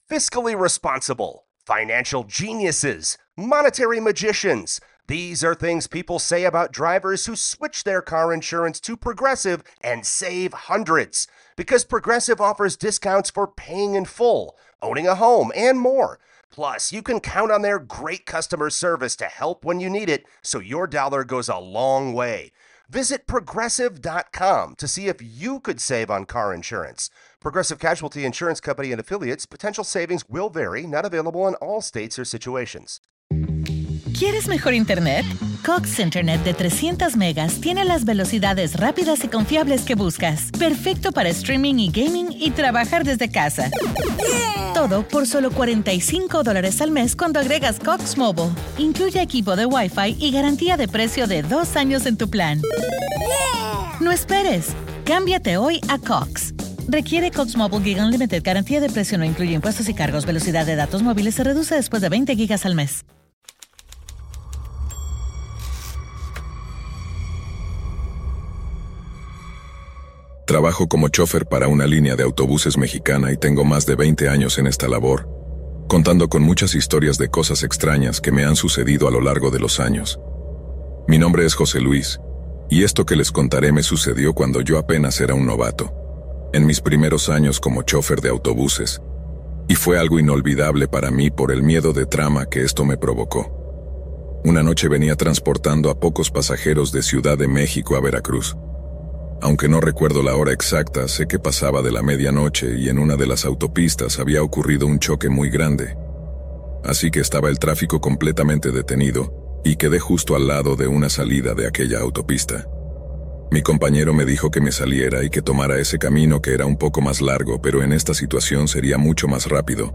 🎙 Narraciones escalofriantes en primera persona🎧 Efectos sonoros que te pondrán la piel de gallina